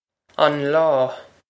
Pronunciation for how to say
on law
This is an approximate phonetic pronunciation of the phrase.